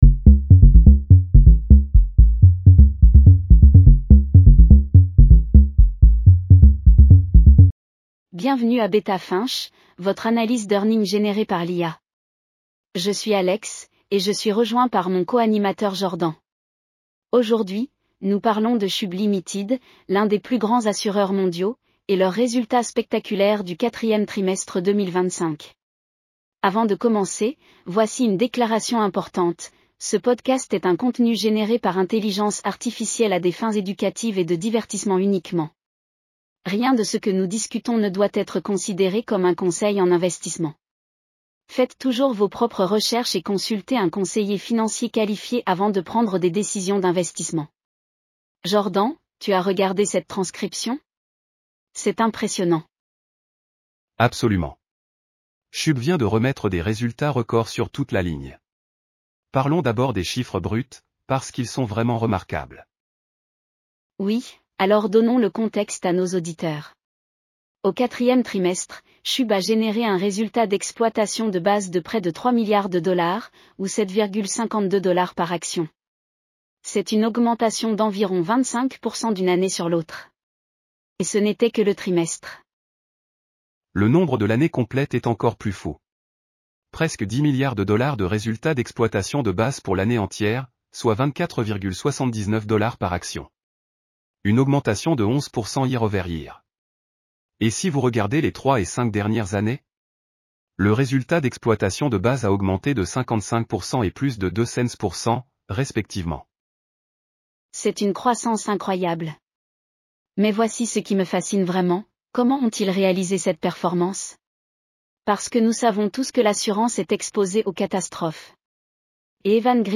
Bienvenue à Beta Finch, votre analyse d'earnings générée par l'IA.